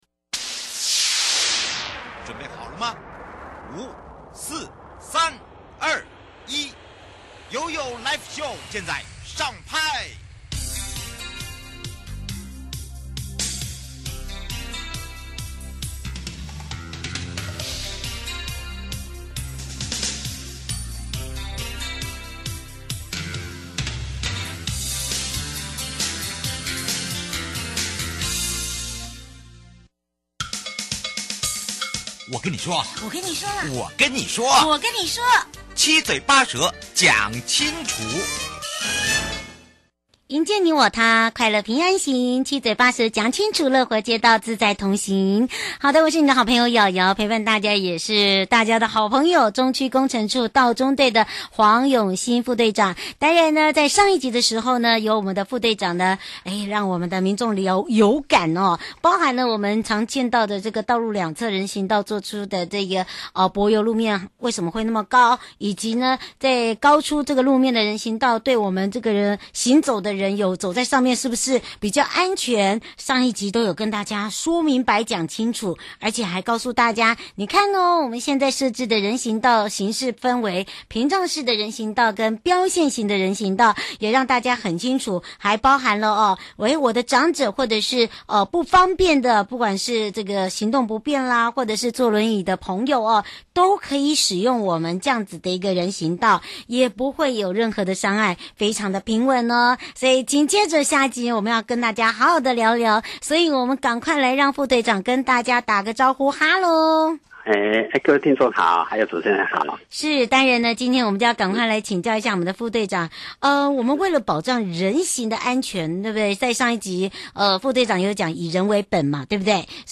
受訪者： 營建你我他-快樂平安行-七嘴八舌講清楚- 中區工程處如何為保障人行安全做出高出路面實體人行道?在道路